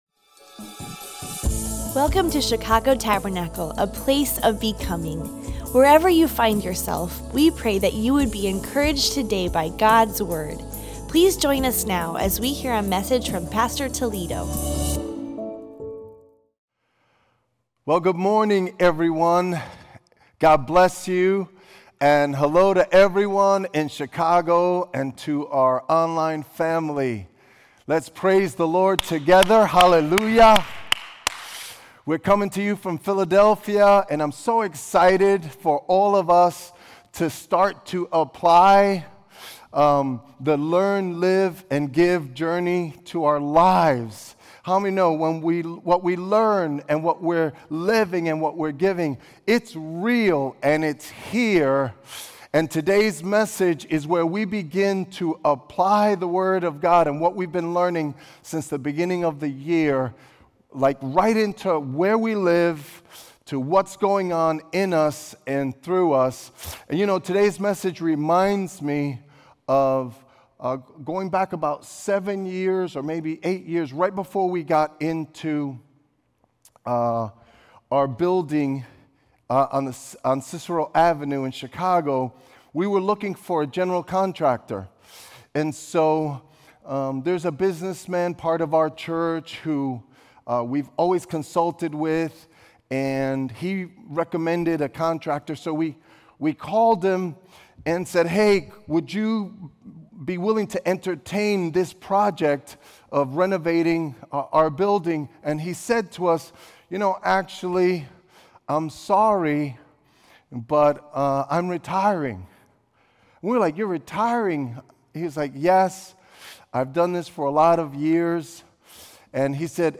Sermons – Page 18 – Chicago Tabernacle